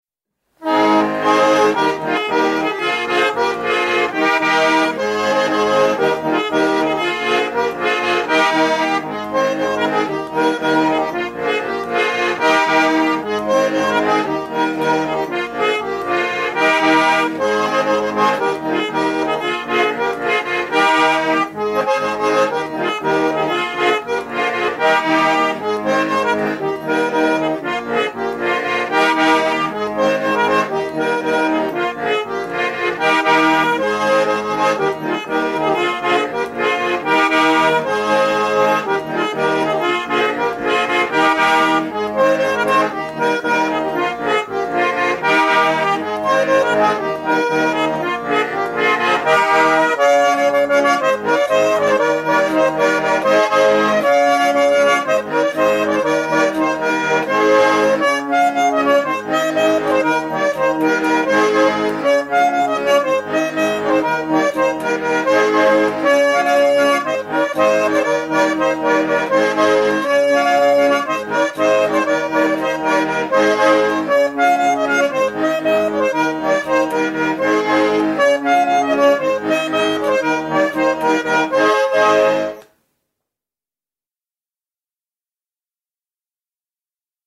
Peri plaadi päält “Juttõ ja laulõ seto aabitsa mano”.